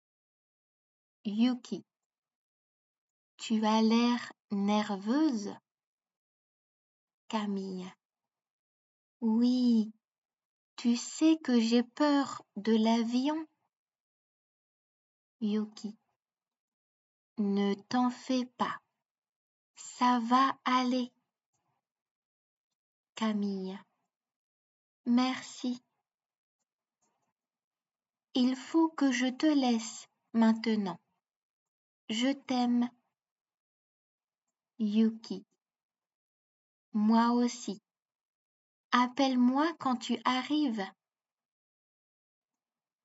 空港で．